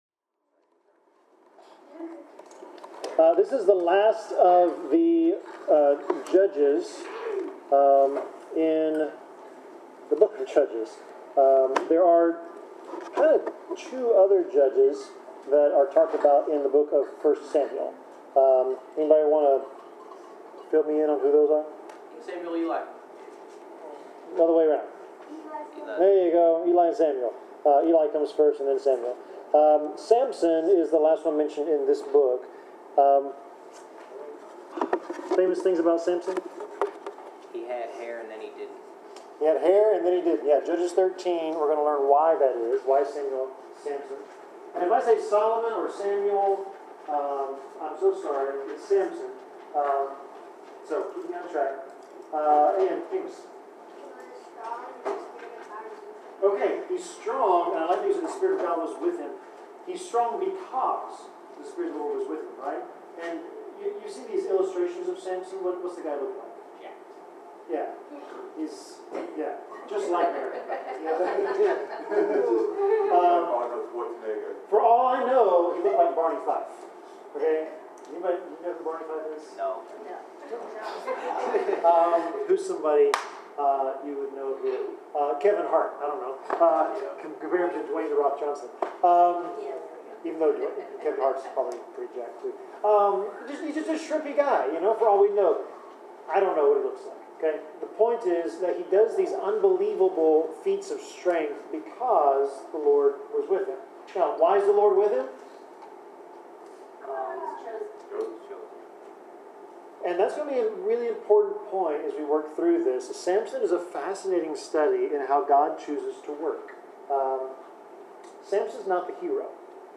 Bible class: Judges 13
Service Type: Bible Class